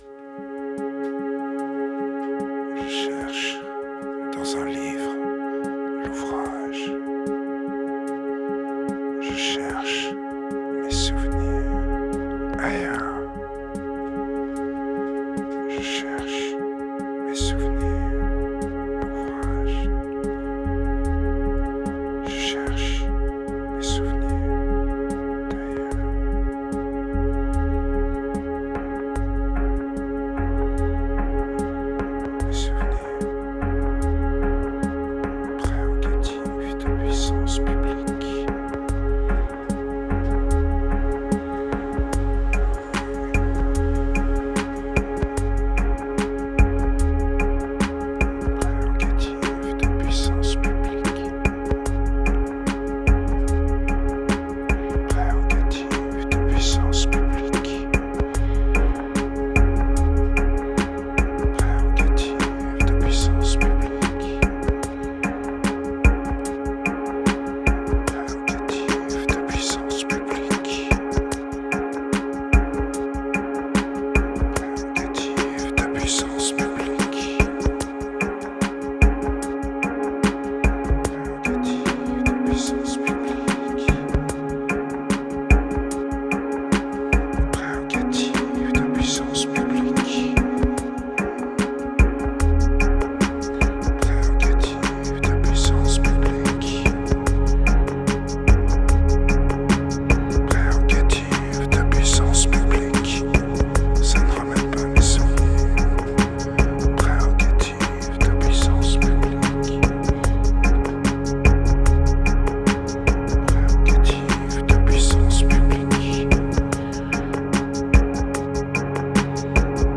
2567📈 - 25%🤔 - 74BPM🔊 - 2015-08-16📅 - -141🌟